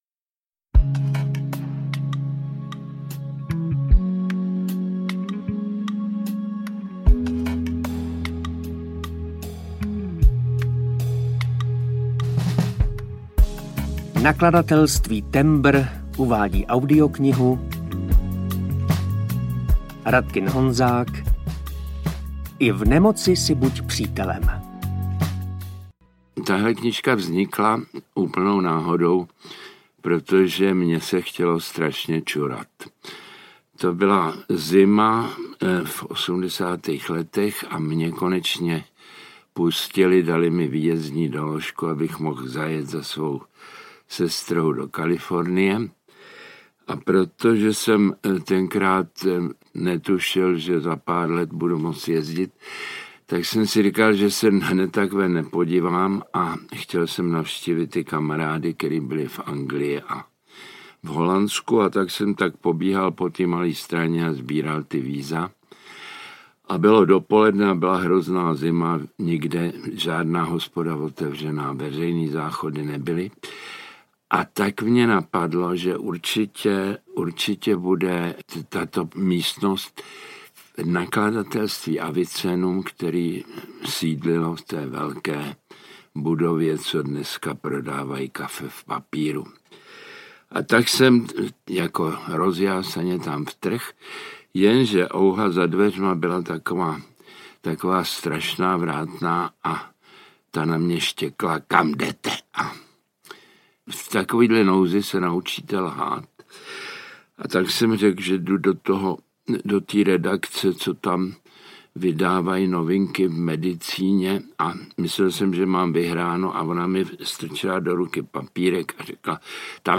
I v nemoci si buď přítelem audiokniha
Ukázka z knihy
• InterpretMartin Myšička